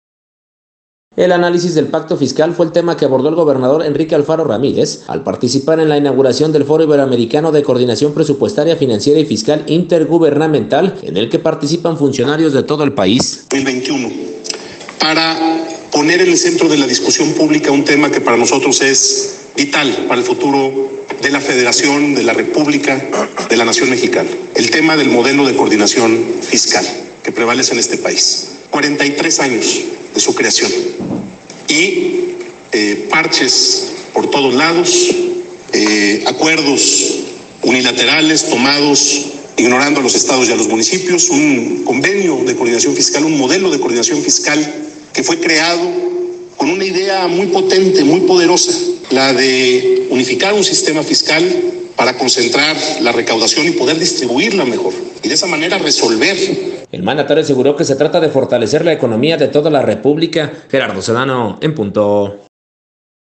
El análisis del pacto fiscal fue el tema que abordó el gobernador del Estado, Enrique Alfaro Ramírez al participar en la inauguración del Foro Iberoamericano de Coordinación Presupuestaria, Financiera y Fiscal Intergubernamental, en el que participan funcionarios de todo el país.